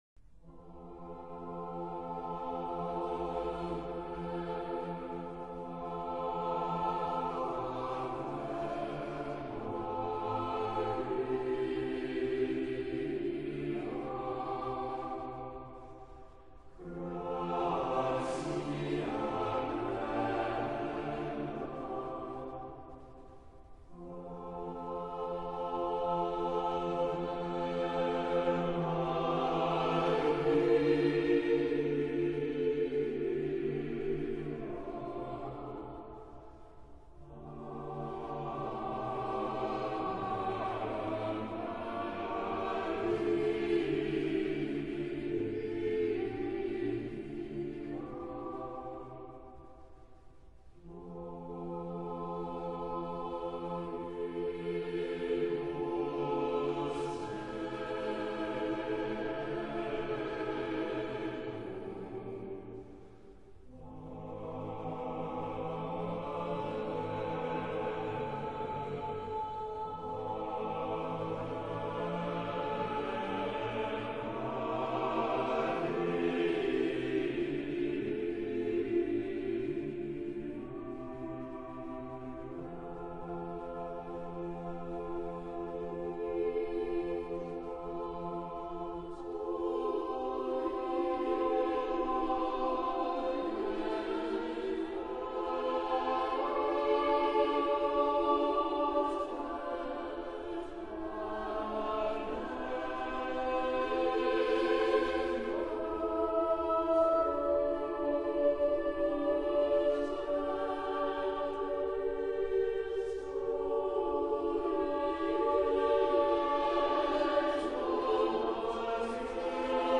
Ave Maria, Hail Mary - Catholic Hymns of Praise.mp3